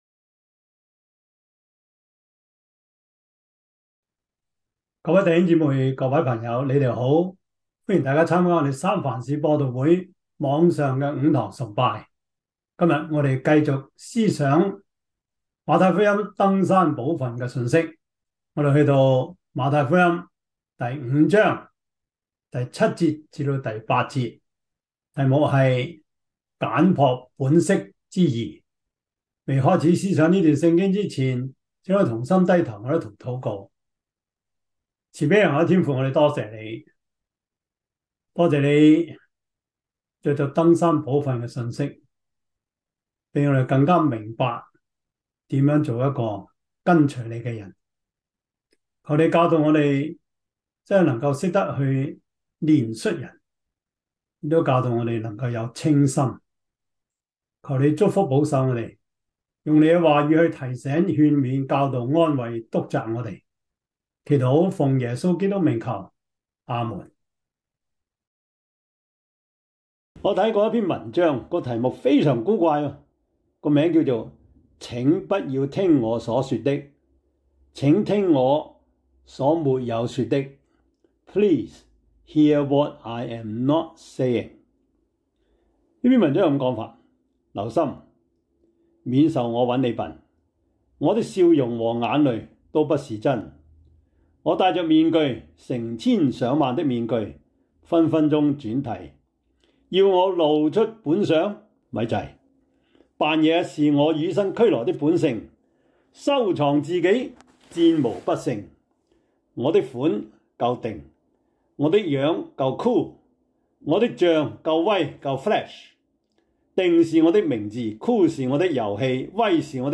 馬太福音 5:7-8 Service Type: 主日崇拜 馬太福音 5:7-8 Chinese Union Version